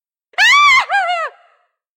Звуки ведьмы